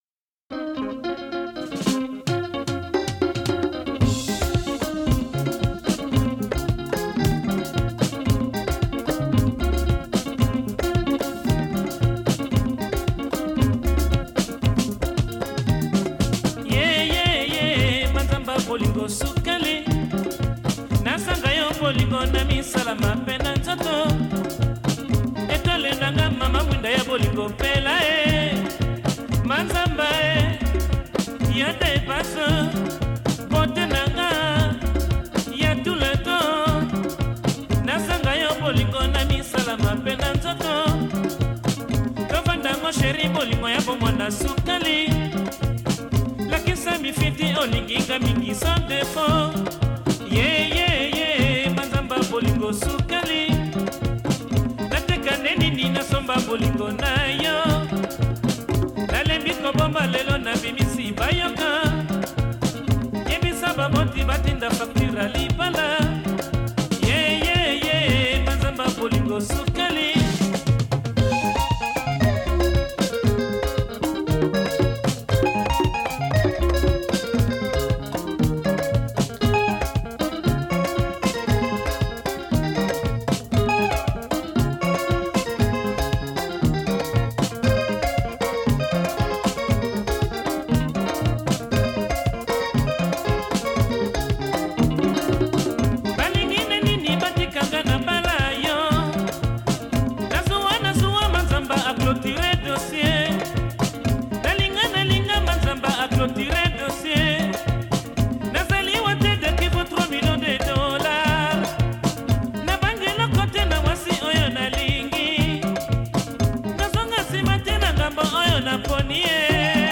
Alain Mabanckou — Interview w